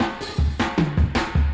Chopped Fill 4.wav